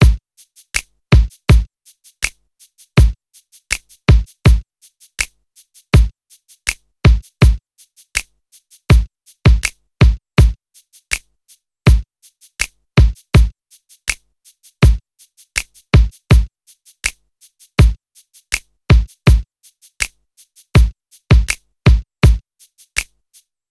06 drums A.wav